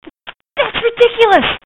Tags: Radio The Curse Of Dracula Play Horror Bram Stoker